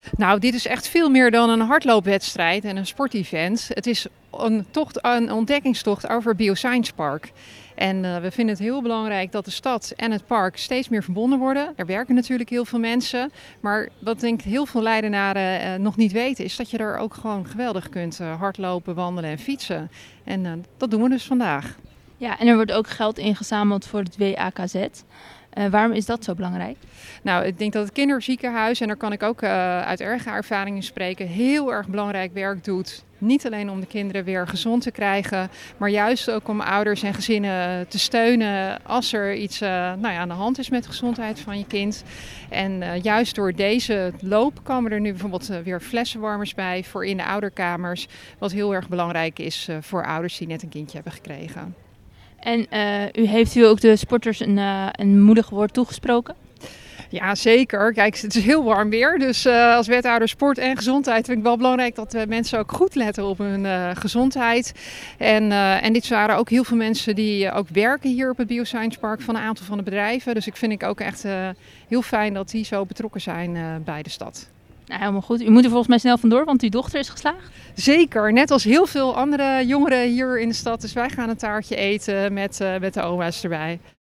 Wethouder Sport en Gezondheid Wietske Veltman over de TK Challenge Bio Science Parkrun.